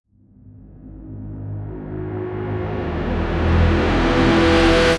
dark cinematic riser, tension building
dark-cinematic-riser-tens-c5zetbgo.wav